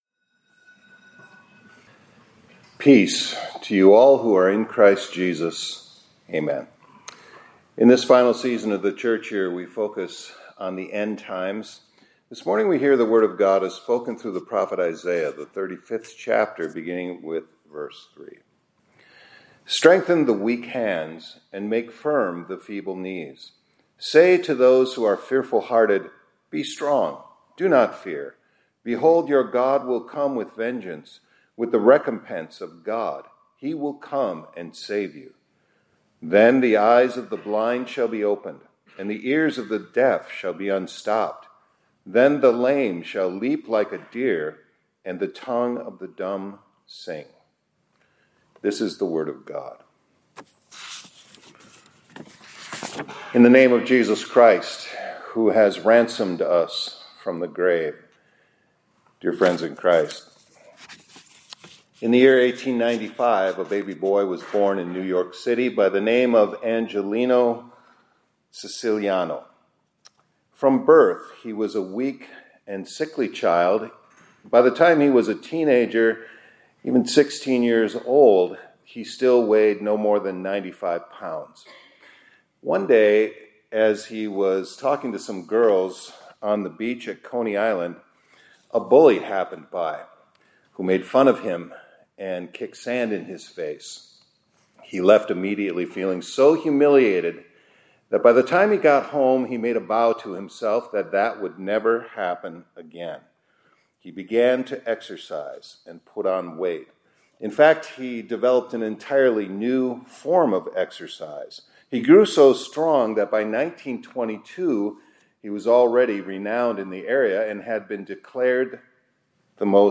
2025-11-07 ILC Chapel — WHEN THE RANSOMED OF THE…